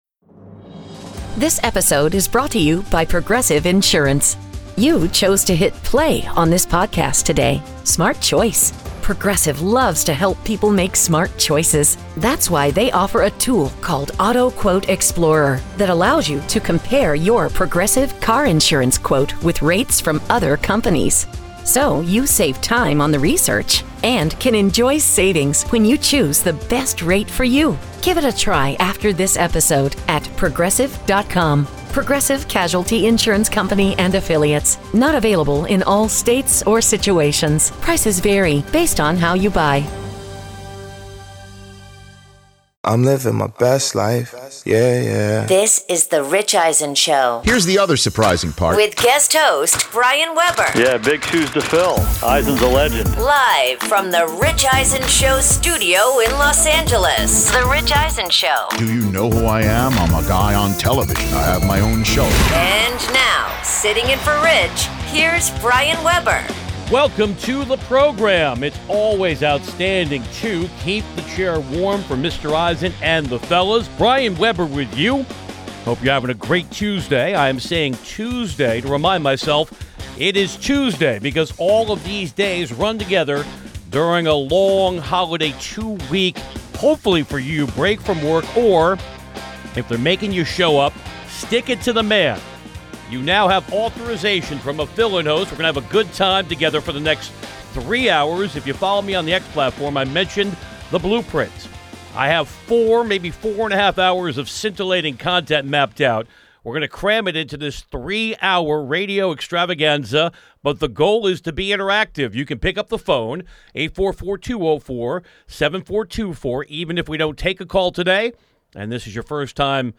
Guest host